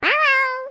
yoshi_hello.ogg